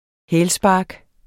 Udtale [ ˈhεːl- ]